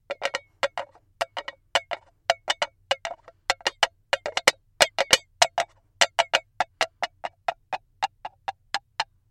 椰子
标签： 失控 HorseGalloping